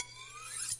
厨房 " 抛光厨房刀 3
描述：抛光菜刀（3/4）。
Tag: 厨房 抛光